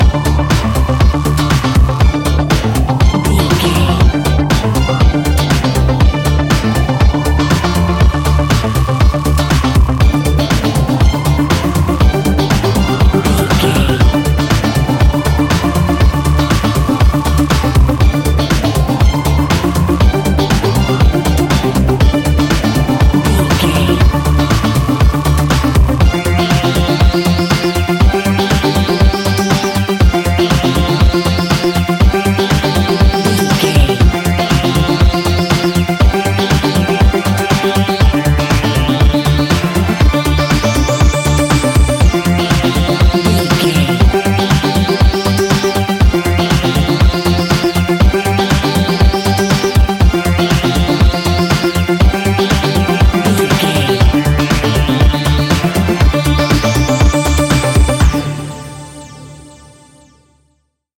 Ionian/Major
D
house
electro dance
synths
techno
trance